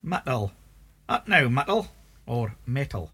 [mUHTl: at now, mUHtl, or at now mEHtl]